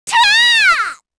Shamilla-Vox_Casting4_kr.wav